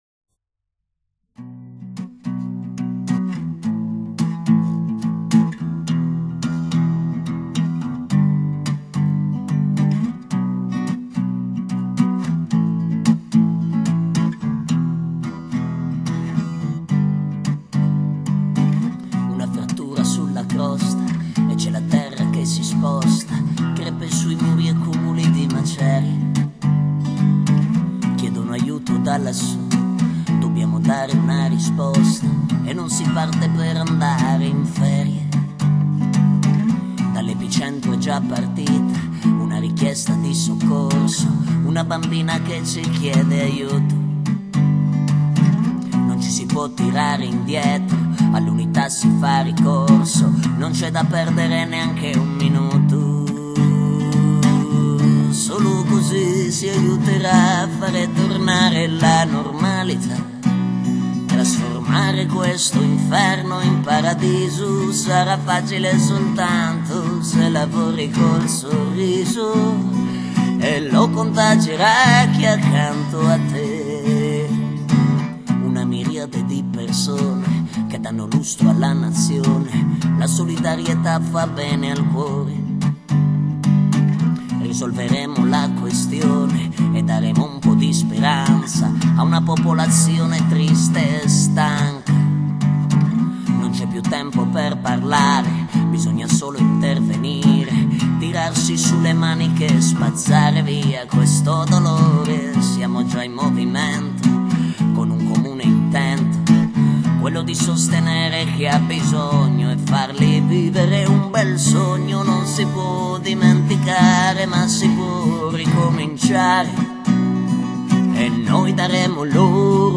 Genere: music